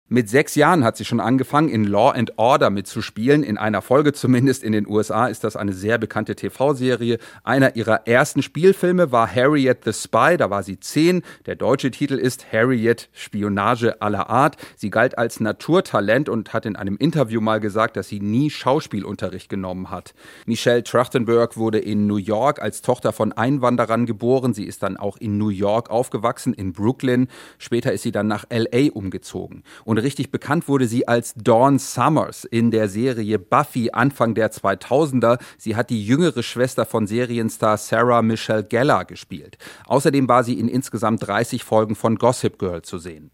Nachrichten Schauspielerin Michelle Trachtenberg: Star der Kultserie „Buffy – Im Bann der Dämonen“